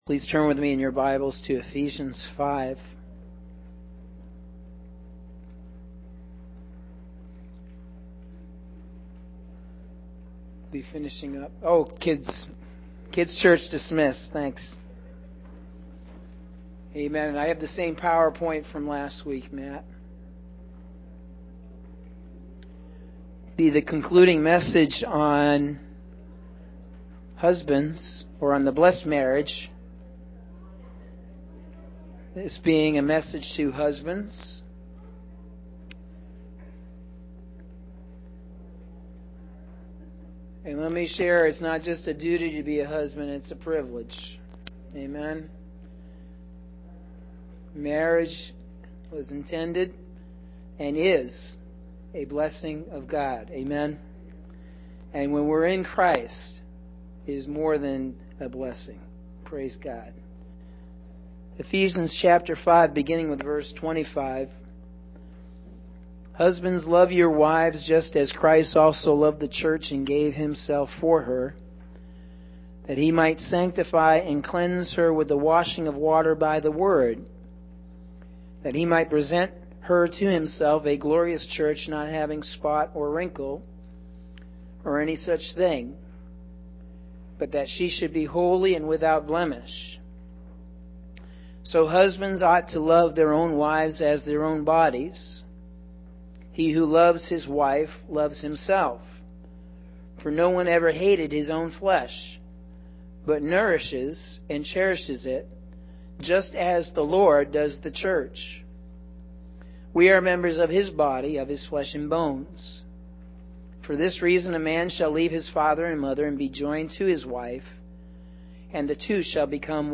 Sunday December 5th – AM Sermon – Norwich Assembly of God